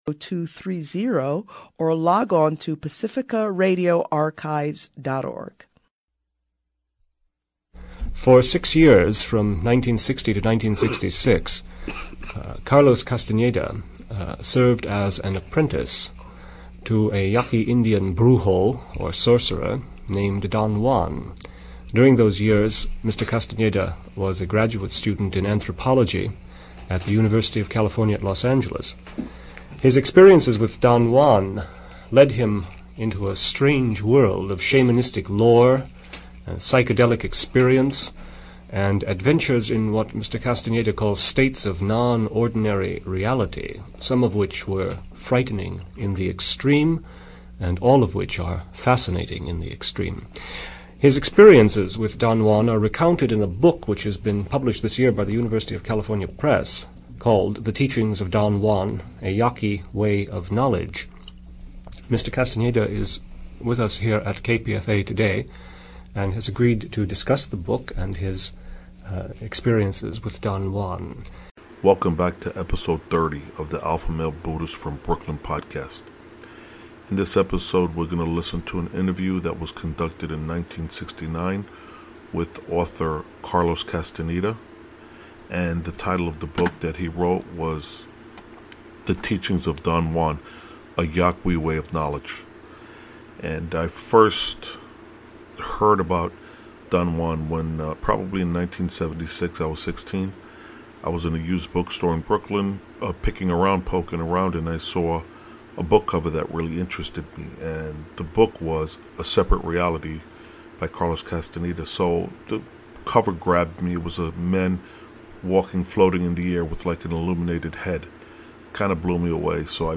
EP 30- Carlos Castaneda interview 1969- Must Listen -Ayahuasca -A man of knowledge lives by acting, not by thinking about acting- Gain an Alternate view of reality- learn to see